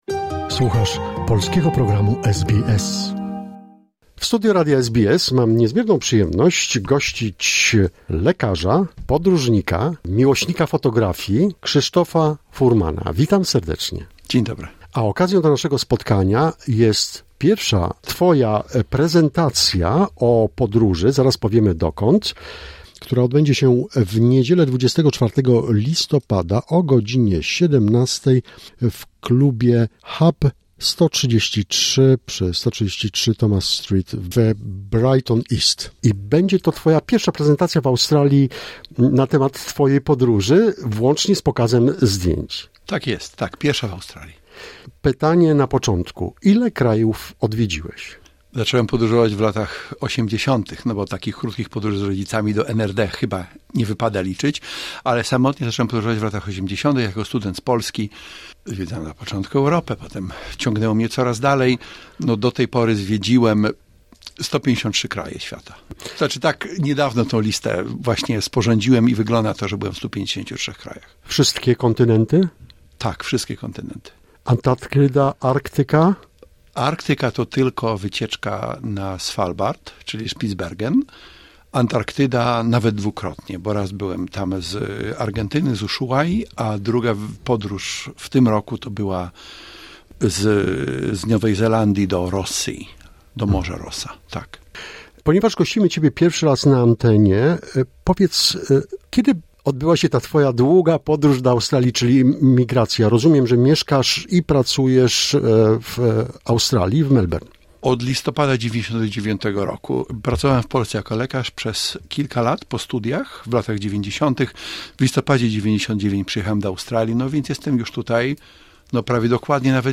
w studio SBS Melbourne